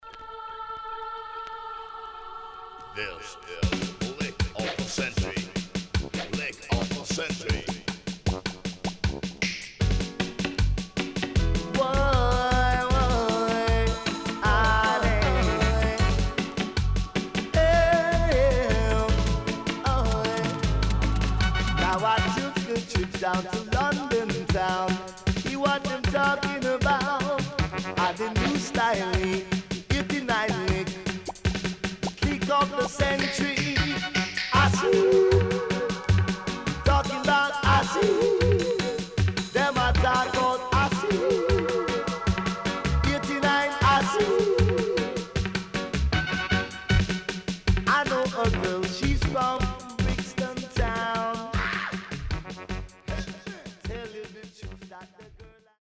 HOME > DISCO45 [DANCEHALL]
CONDITION SIDE AA:VG+
riddim
SIDE AA:少しチリノイズ入ります。